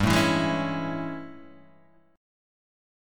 AM7/G chord {3 0 2 1 2 0} chord